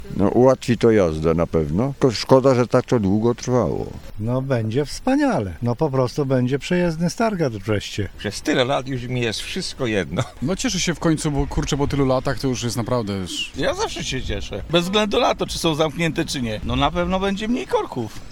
Zapytaliśmy stargardzkich kierowców, co sądzą o zakończeniu remontów i czy cieszą się z tej zmiany.